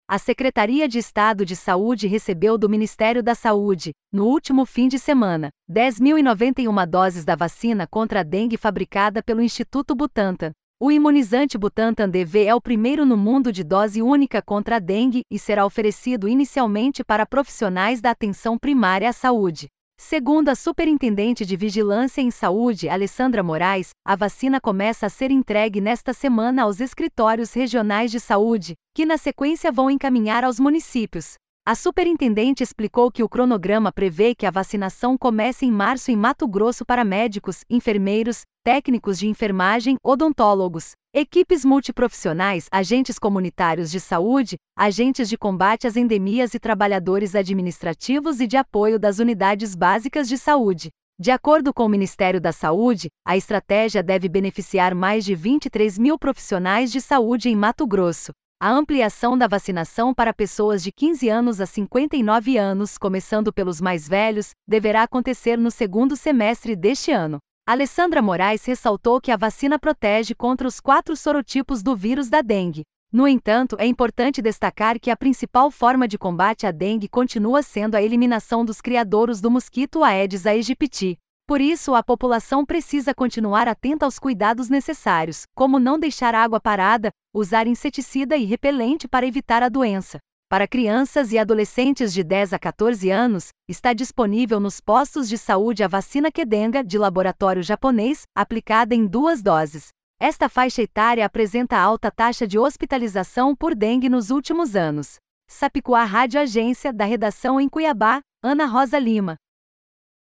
Boletins de MT 24 fev, 2026